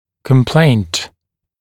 [kəm’pleɪnt][кэм’плэйнт]жалоба (пациента при обращении к врачу)